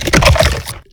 flesh3.ogg